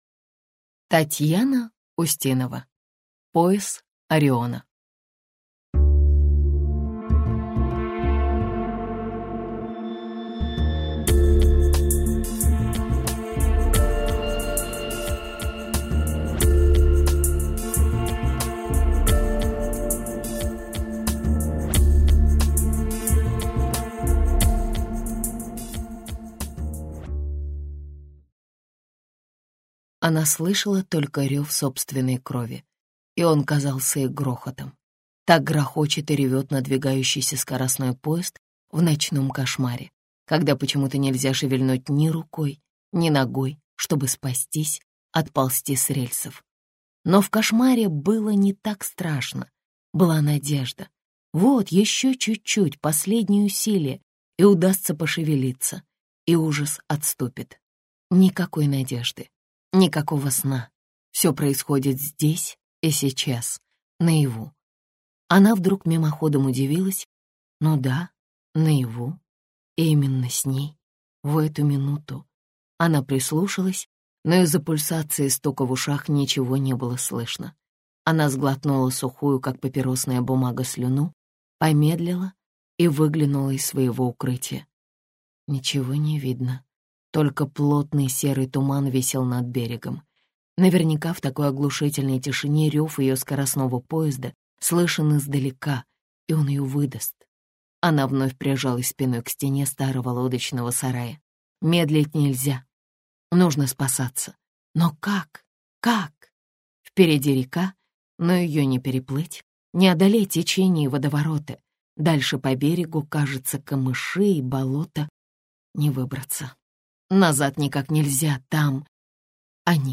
Аудиокнига Пояс Ориона - купить, скачать и слушать онлайн | КнигоПоиск